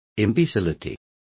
Complete with pronunciation of the translation of imbecility.